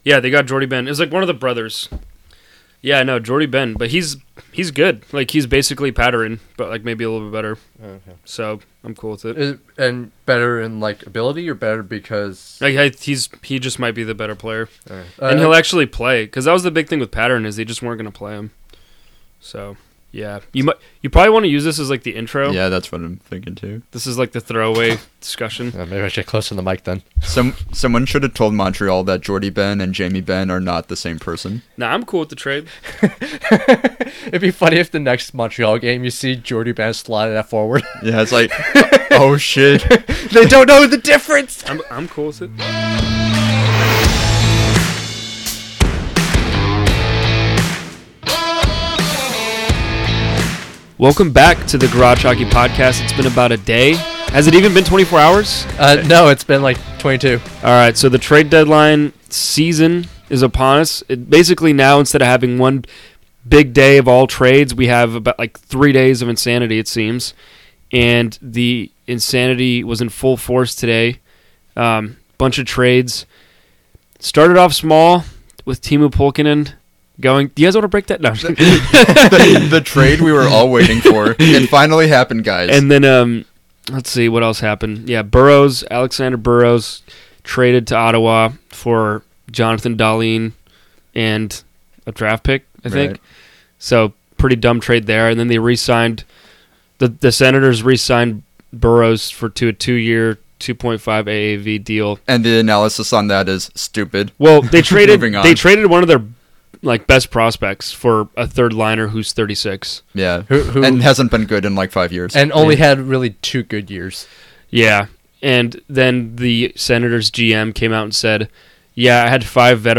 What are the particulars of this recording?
return to the studio for the second consecutive night to talk about more trades!